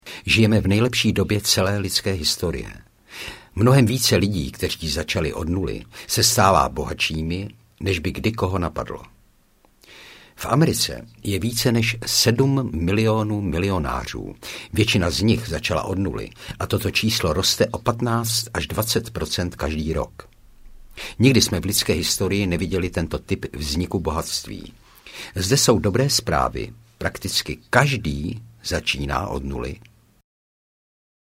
Absolutní základy finanční nezávislosti audiokniha
Ukázka z knihy